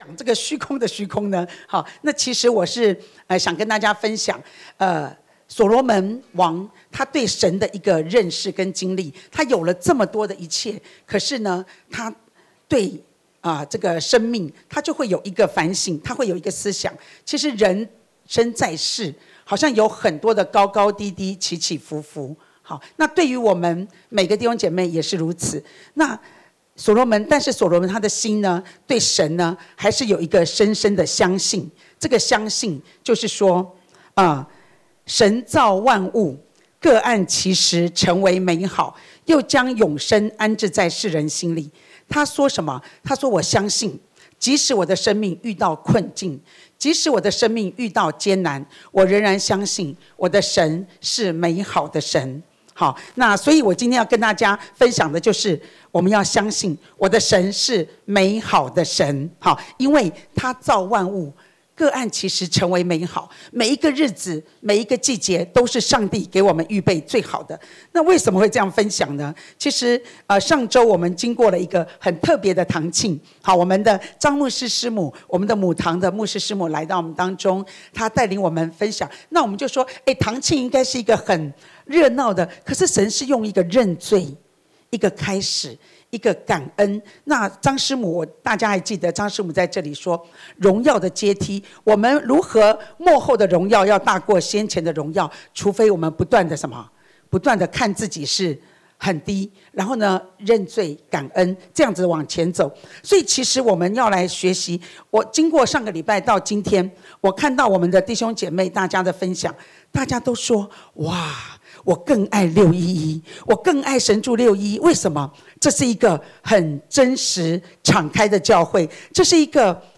講道筆記